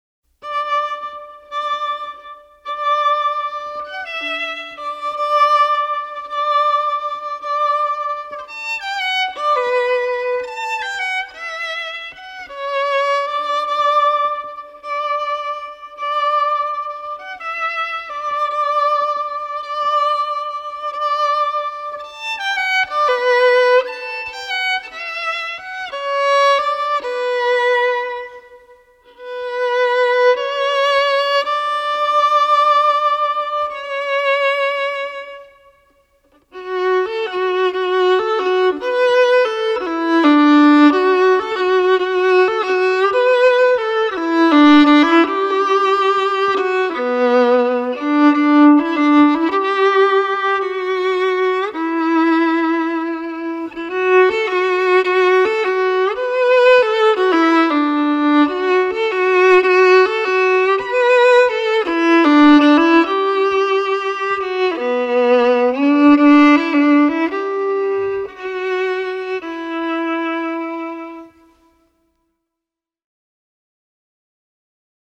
Solo violin tracks recorded at FTM Studio in Denver Colorado